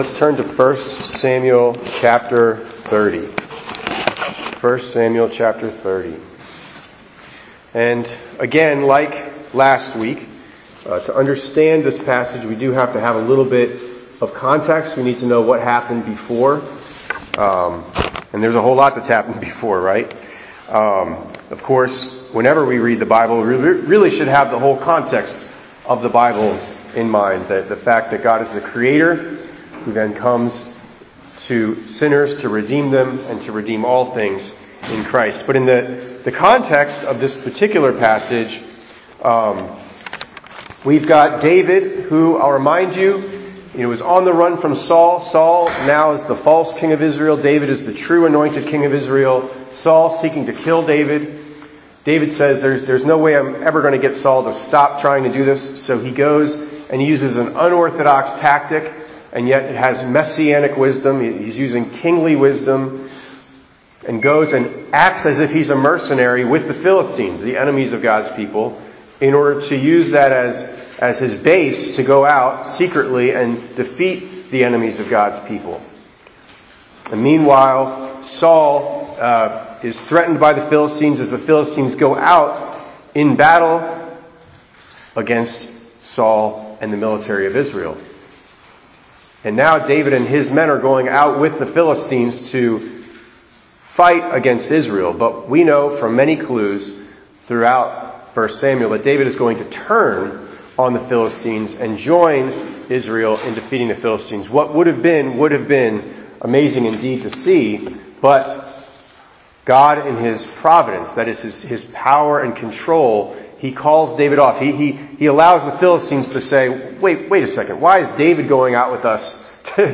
4_19_26_ENG_Sermon.mp3